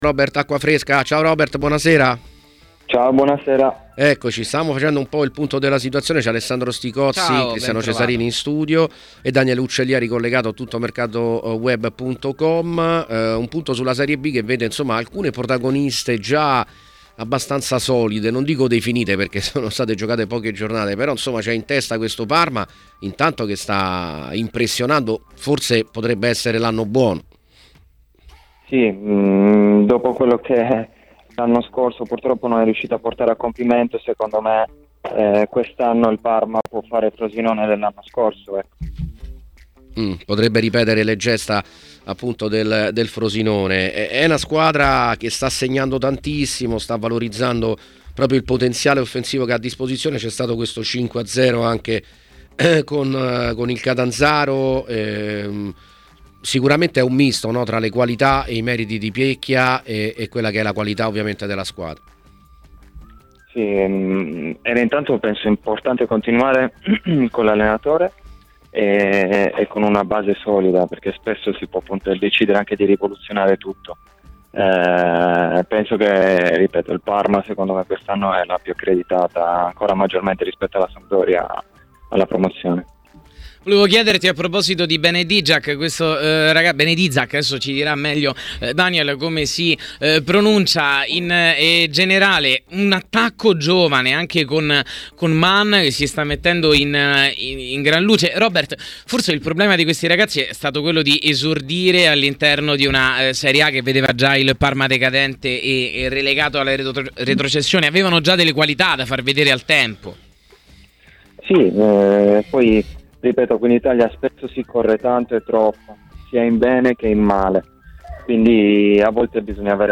Robert Acquafresca è intervenuto durante il programma “Piazza Affari” a TMW Radio per commentare vari temi, in particolare riguardo il Parma: “Dopo quello che lo scorso anno non è stato compiuto il Parma può essere il Frosinone dello scorso anno.